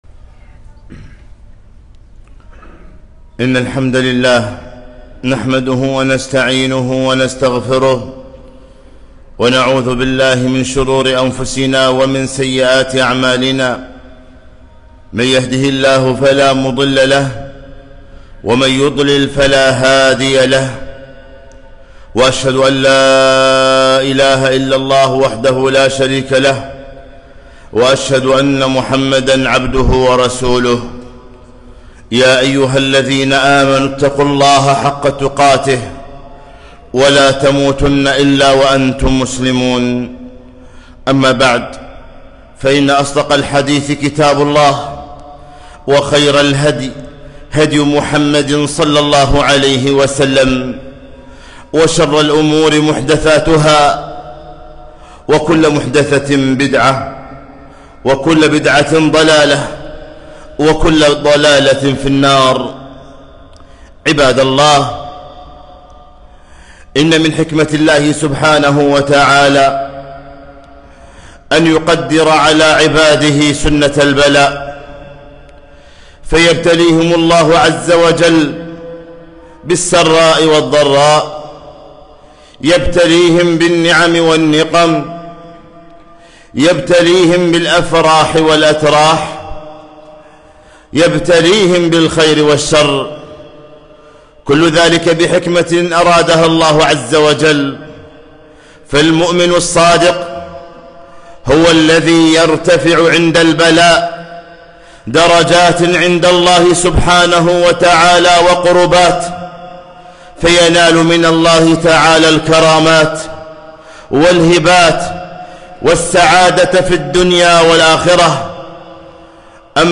خطبة - الصبر عند البلاء